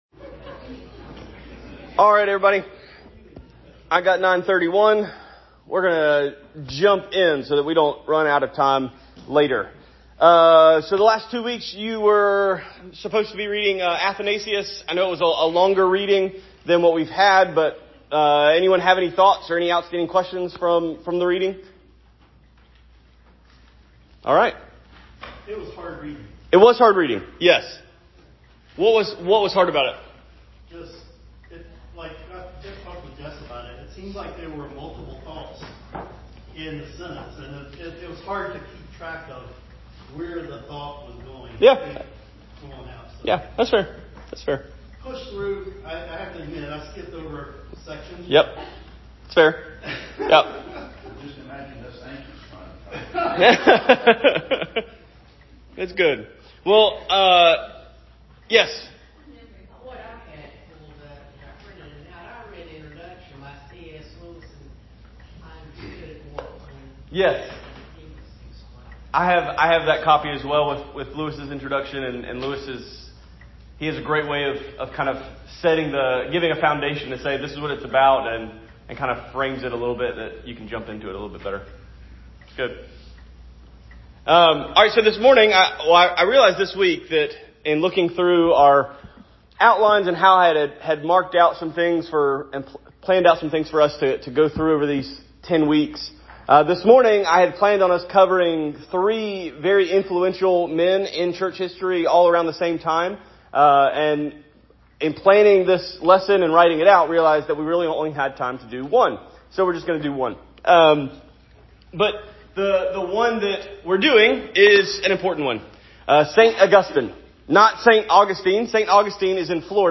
Series: Sunday School Lesson